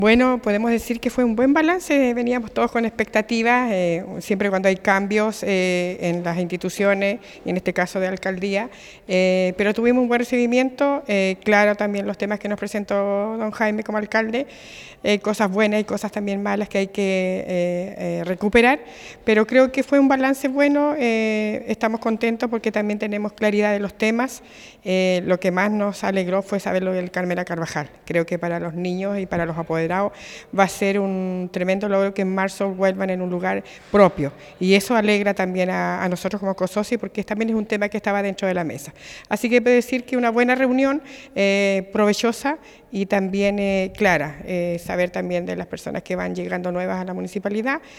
El miércoles recién pasado, la sala de sesiones del municipio de Osorno fue el escenario de la primera reunión del año del Consejo de Organizaciones de la Sociedad Civil, COSOCI. En esta instancia clave, los dirigentes sociales y las autoridades locales analizaron el trabajo realizado hasta la fecha, aclararon dudas y discutieron los desafíos y expectativas para el año 2025.